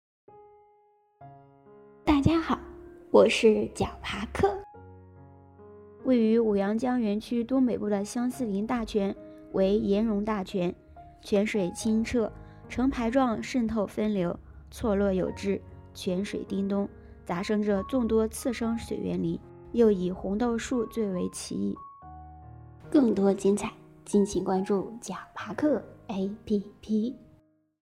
展开更多 岩溶大泉 ----- 豌豆荚 解说词: 位于武阳江园区东北部的相思林大泉为岩溶大泉，泉水清澈，成排状渗透分流，错落有致，泉水叮咚，杂生着众多次生水源林，又以红豆树最为奇异。